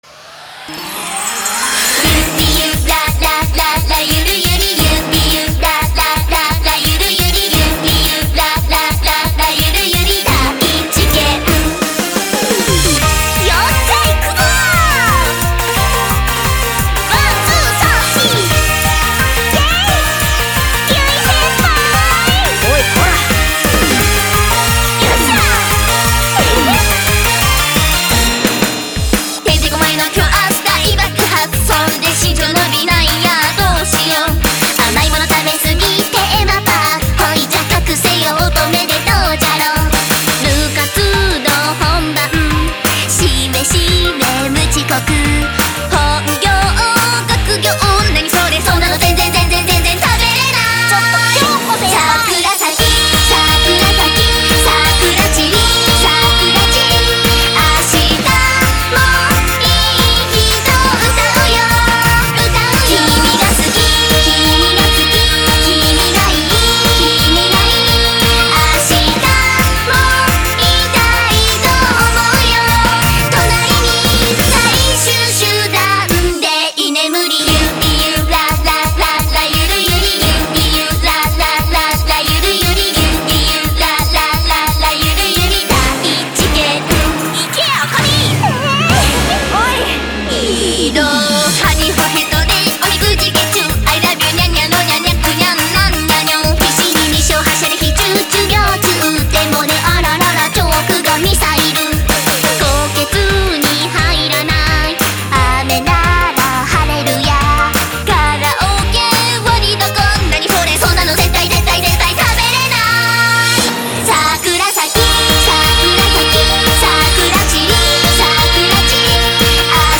Жанр: Anime